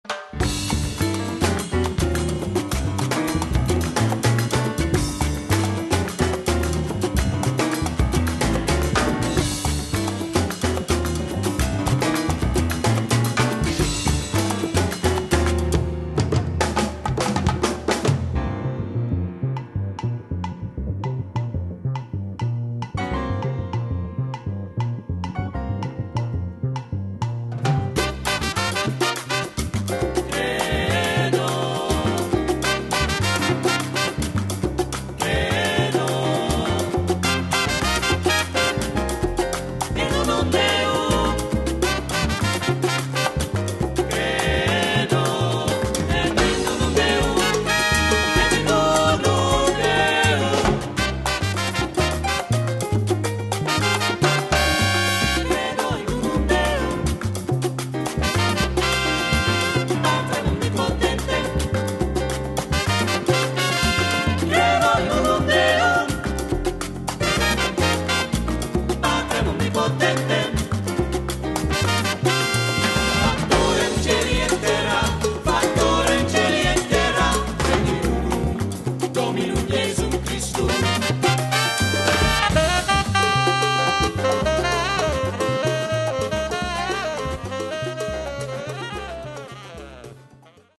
Latin jazz
Category: combo
Style: mambo
Solos: tenor, percussion
Instrumentation: tenor, trumpet 1-2, SATB, rhythm